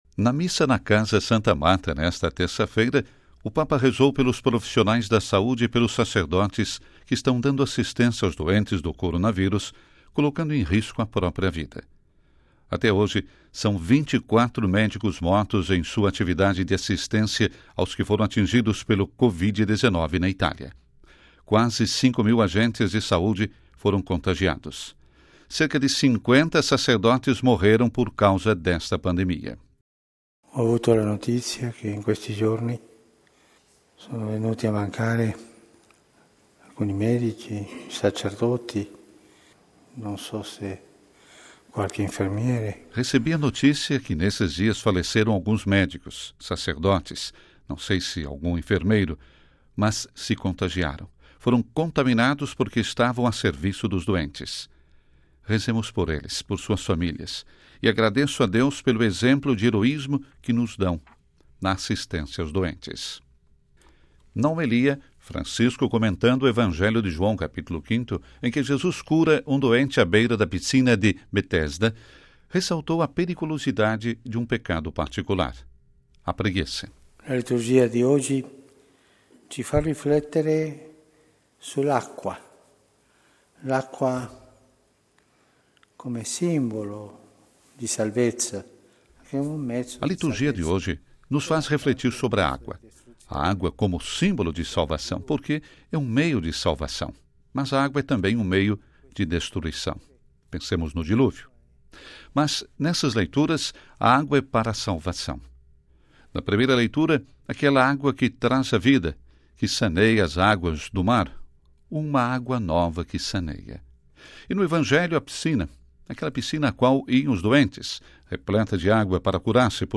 Na Missa da manhã desta terça-feira (24/03) na Casa Santa Marta o Papa agradeceu aos médicos, aos enfermeiros e aos sacerdotes comprometidos na assistência aos doentes de Covid-19: um exemplo de heroísmo. Na homilia, chamou a atenção para o pecado da preguiça.